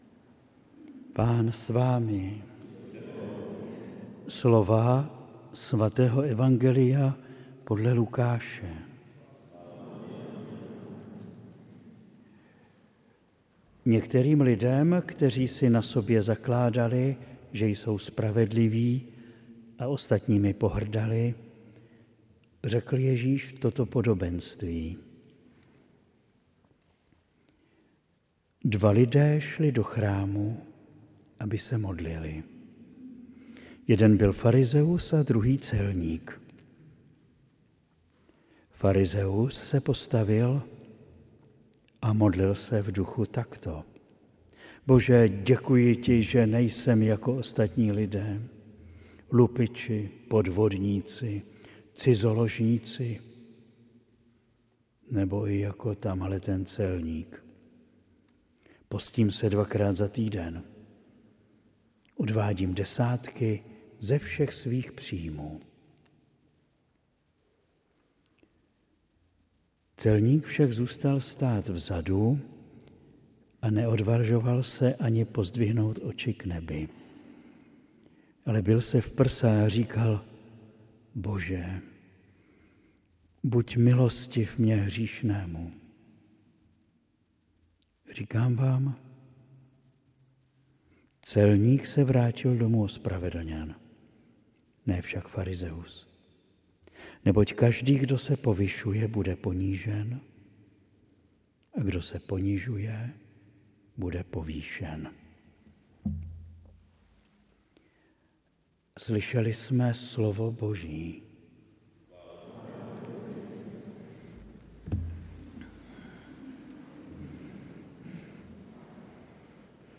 Římskokatolická farnost u kostela sv. Fabiána a Šebestiána Praha-Liboc
kázání